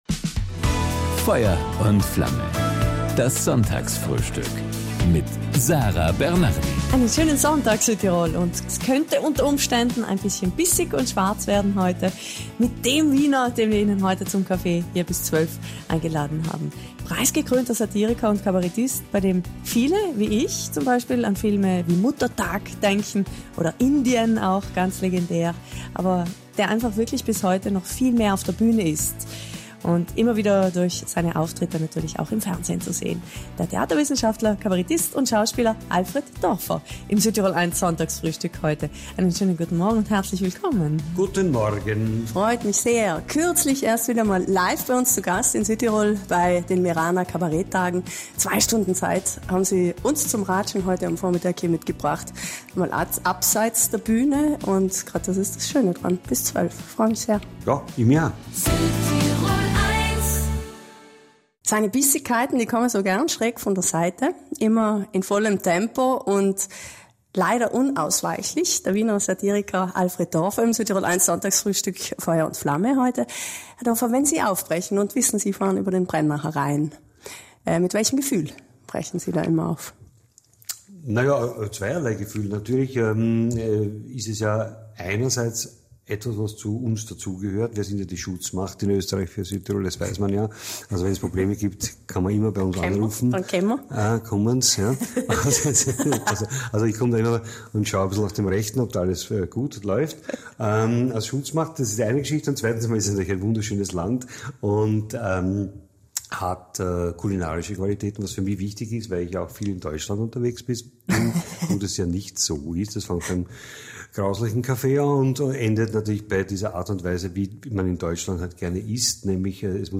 Also hat er am Sonntag zu Gast im Südtirol1-Sonntagsfrühstück vor allem eines gemacht – erzählt: von der Berufskrankheit der Satiriker, seiner Schwäche für Südtirol und manchen Entwicklungen der letzten Zeit, die er durchaus mit Wohlwollen beobachtet…